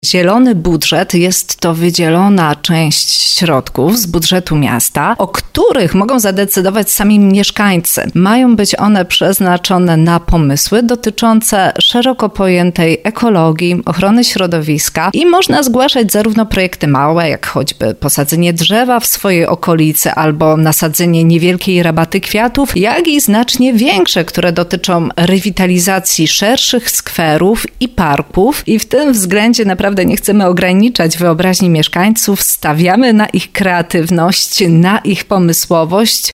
Chętnie je realizujemy i obserwujemy, jak dzięki współpracy mieszkańców z samorządem nasze miasto staje się coraz piękniejsze – mówi Krystian Grzesica, burmistrz Bierunia.